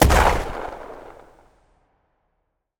explosion_small_02.wav